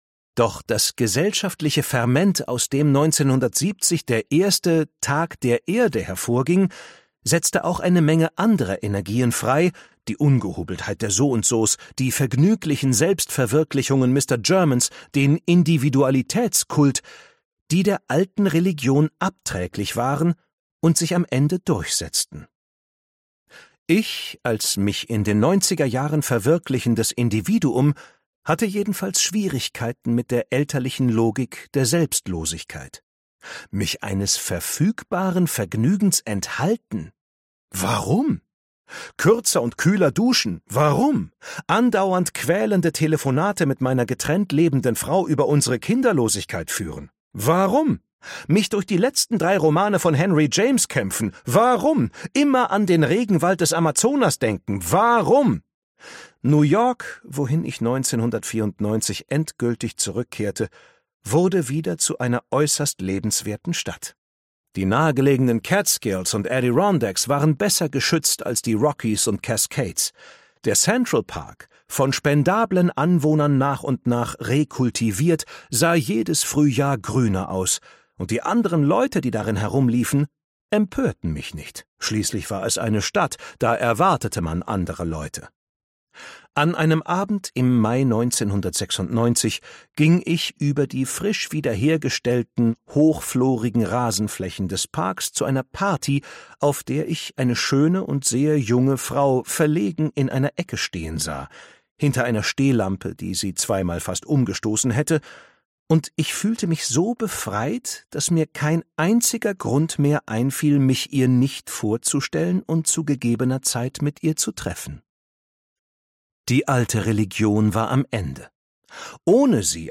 Ein vielfarbiges, mitunter komisch-trotziges Selbstporträt eines Menschen in seiner Zeit. Ausgabe: Ungekürzte Lesung, Hörbuch Download, Hördauer: 7h 40min